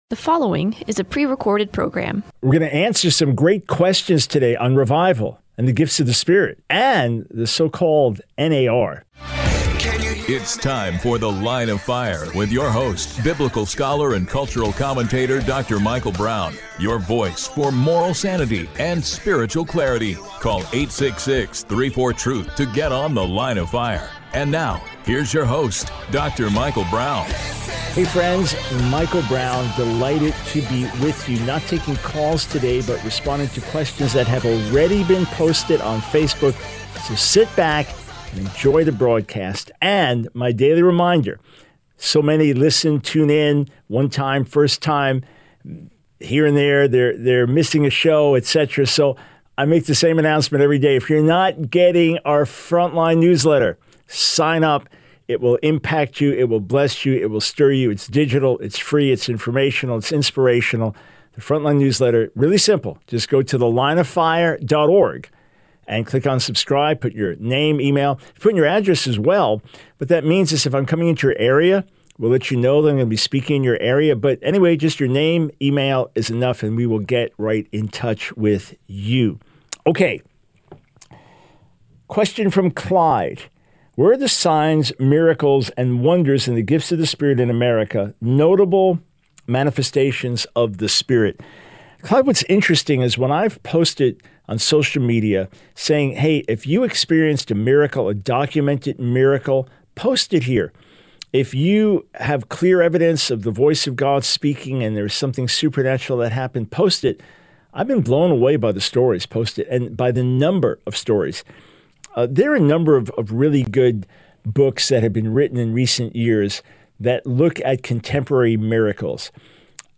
The Line of Fire Radio Broadcast for 06/21/24.